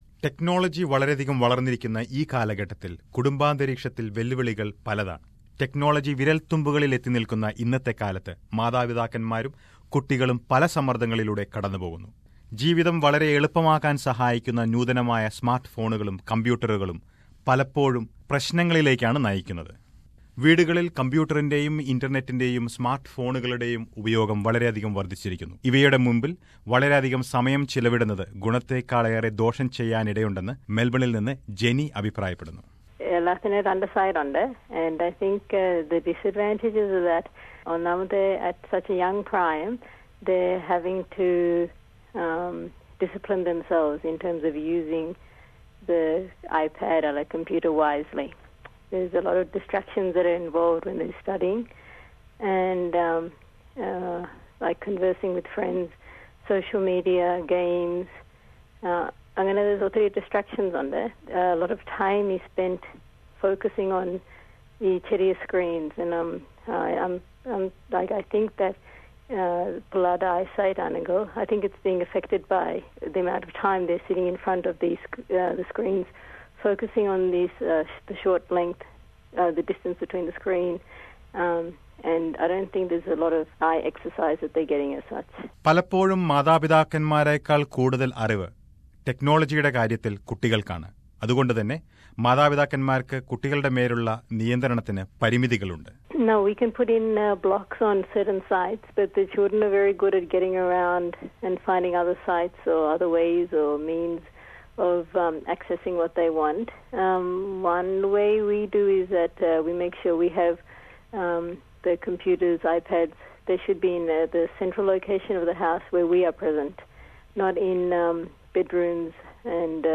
Let us listen to a report on this.